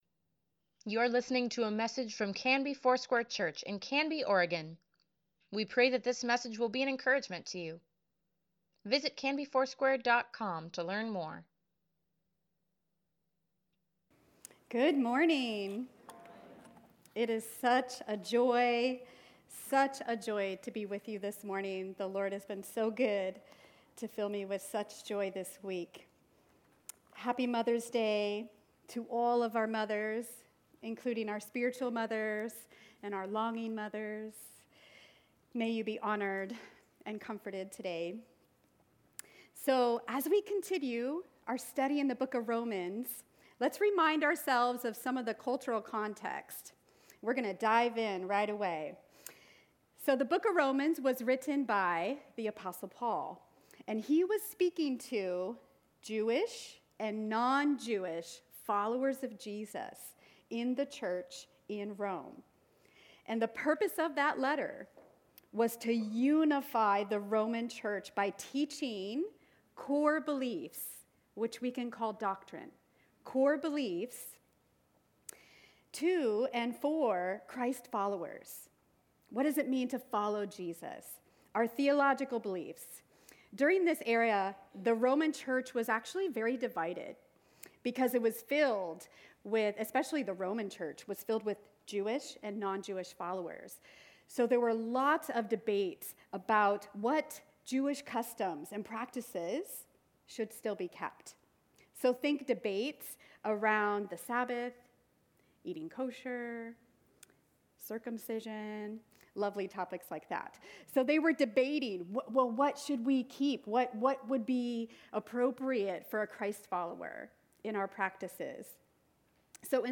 Sunday Sermon | May 12, 2024